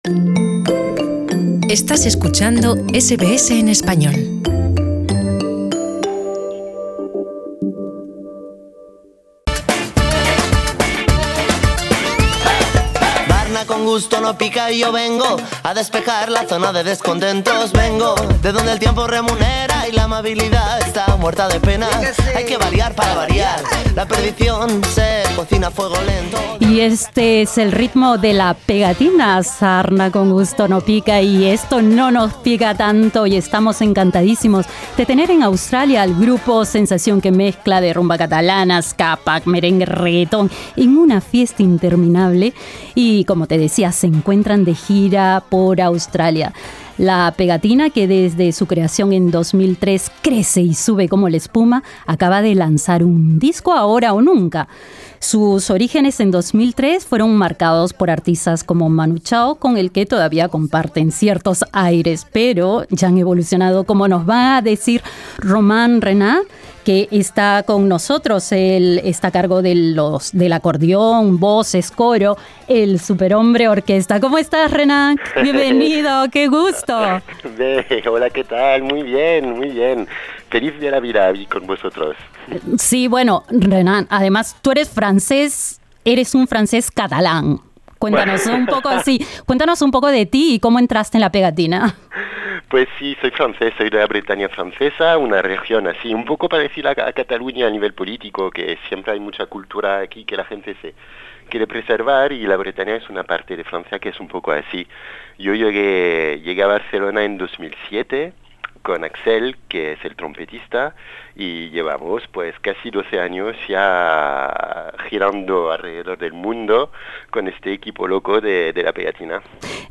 SBS conversó con